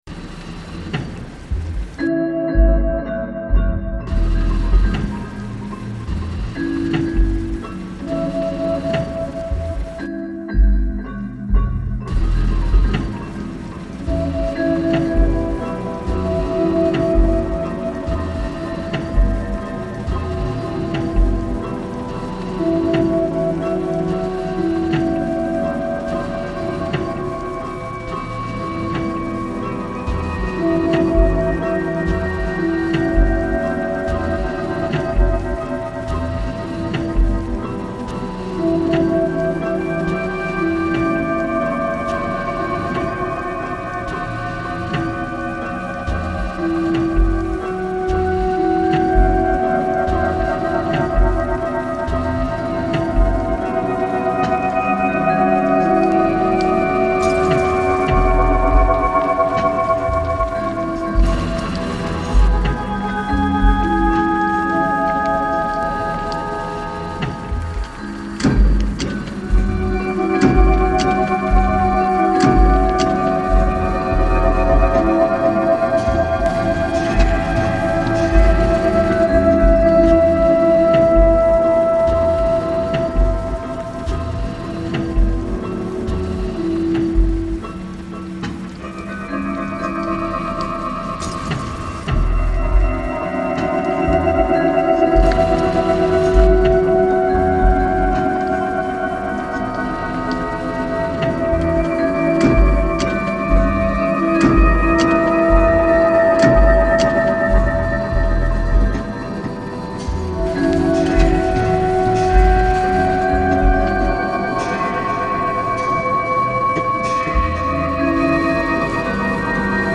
Venetian boat crane reimagined